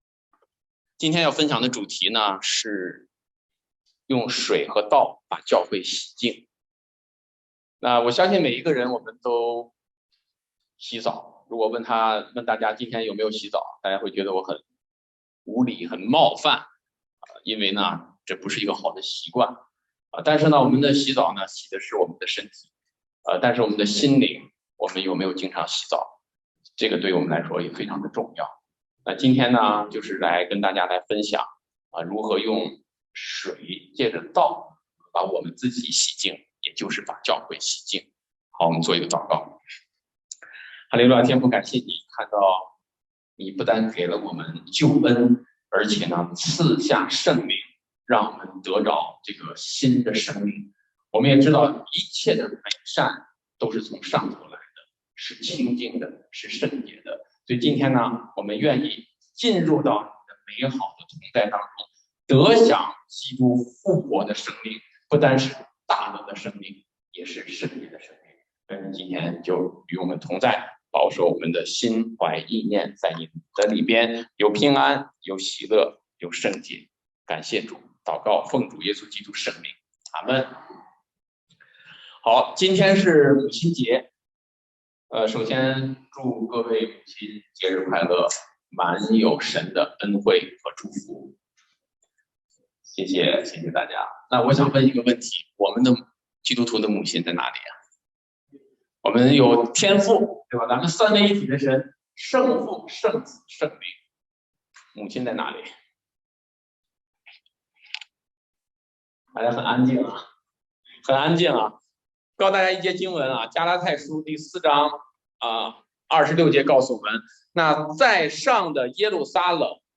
请听牧师证道: 音频: 注1: 某些手機開音频, 需点击2-3次。